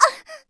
damage_1.wav